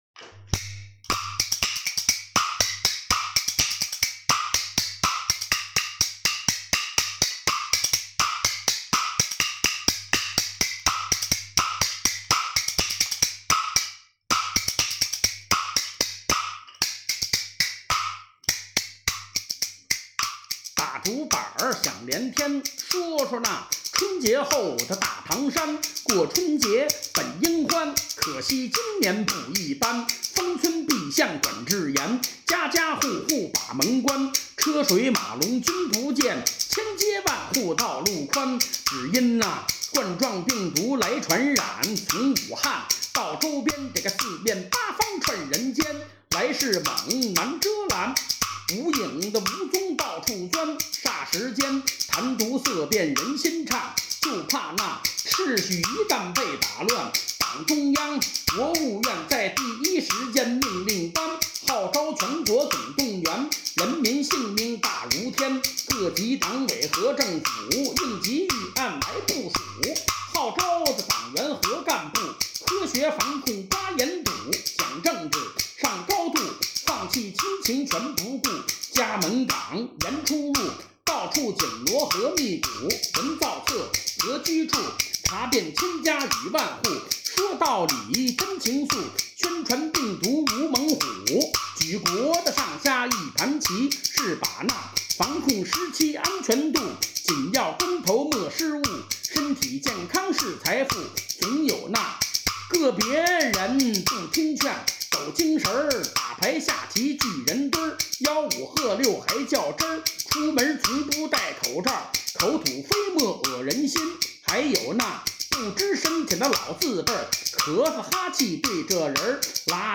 快板《战后必是艳阳天》